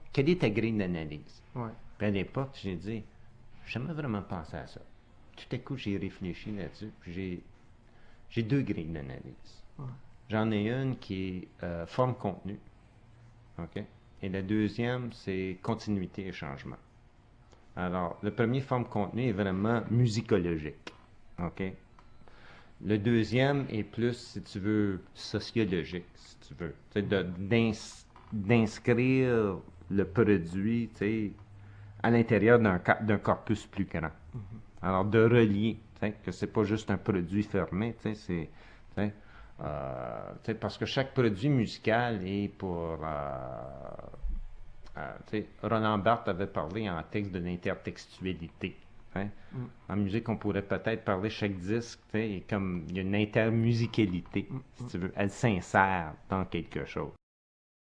L’intégrale de l’entrevue (en cassette DAT numérique) ainsi qu’un résumé détaillé et indexé dans la base de données de la Phonothèque sont disponibles pour la consultation au bureau de la Phonothèque québécoise.